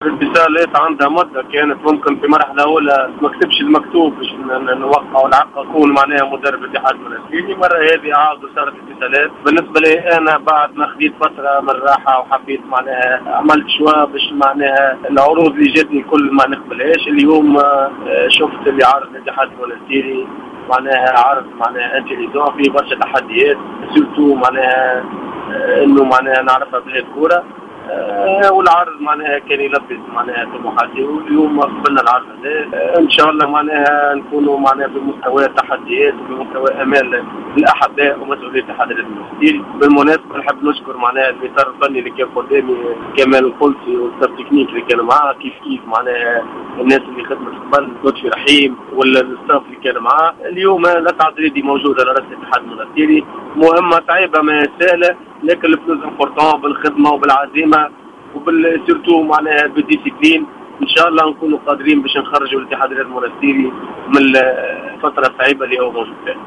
و أكد الدريدي في تصريح لجوهرة أف أم أن الإتصالات قد تمت منذ مدة مع الهيئة المديرة للإتحاد المنستيريمضيفا أنه قد قبل العرض بما أنه يلبي رغباته و طموحاته مبينا أنه يطمح لتقديم الإضافة للفريق و إعادته إلى سكة الإنتصارات بعد البداية المتعثرة في سباق البطولة.